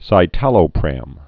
(sī-tălō-prăm)